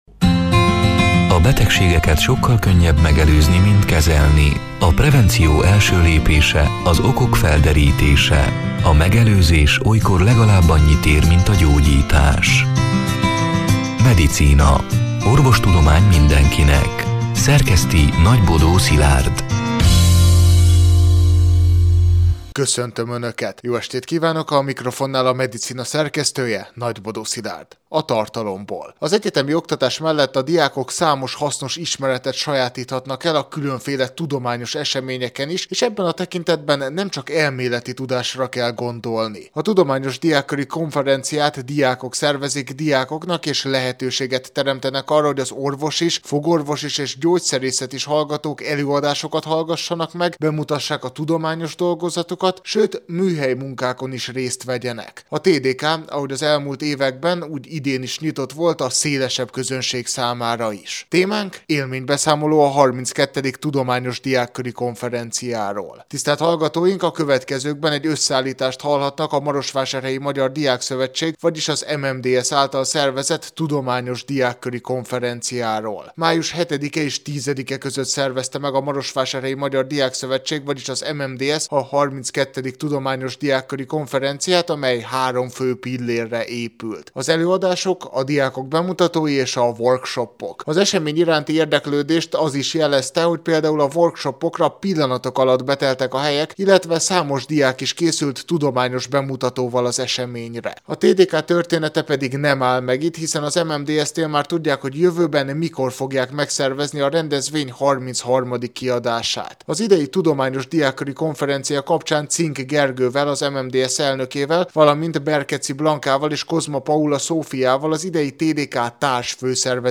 A Marosvásárhelyi Rádió Medicina (elhangzott: 2025. május 14-én, szerdán este nyolc órától) c. műsorának hanganyaga: